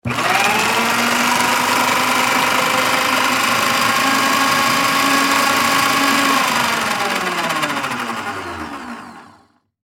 На этой странице собраны звуки работы шредера — от плавного жужжания до резкого измельчения бумаги.
Шум работающего шредера